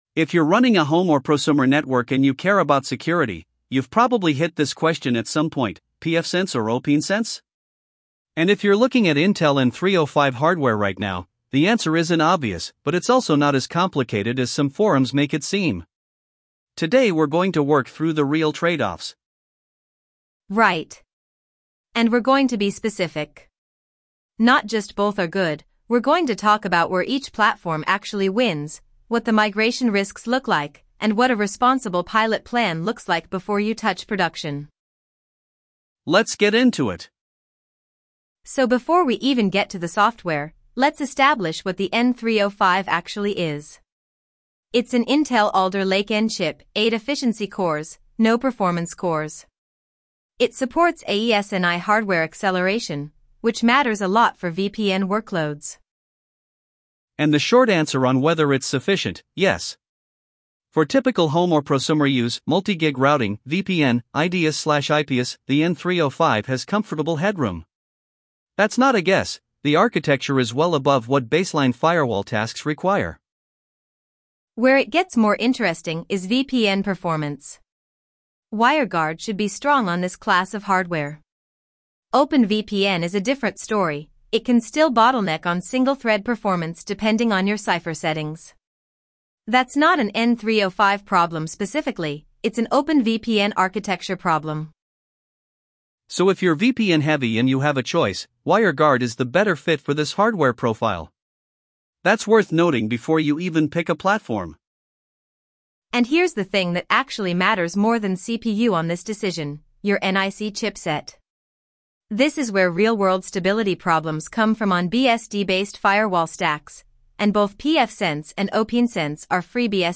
I corrected that with a dual-voice pipeline: parse Host A/Host B turns, synthesize each turn with distinct voices, insert pauses, then stitch to one MP3.
Artifact: dual-voice audio example #2